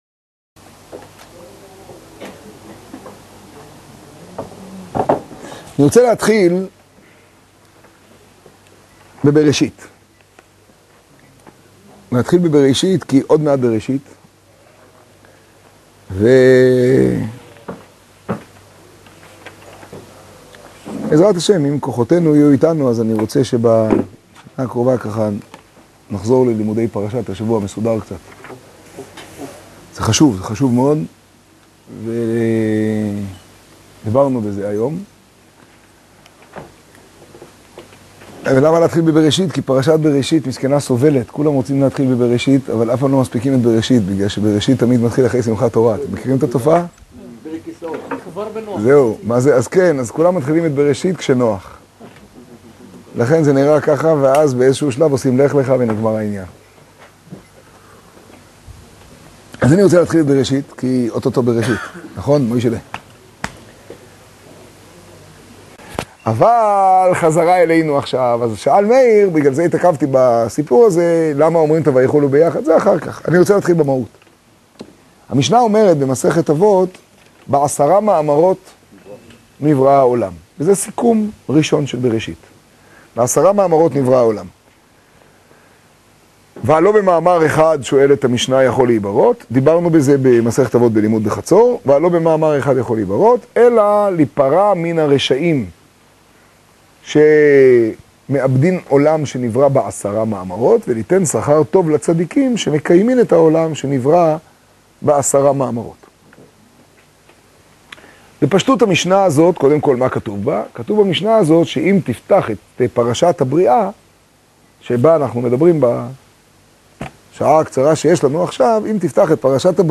האזנה קטגוריה: שיעור , שיעור במגדל , תוכן תג: בראשית , חומש , תשעג → בין צום השביעי לצום העשירי סוכות – זמן שמחתינו תשעג ←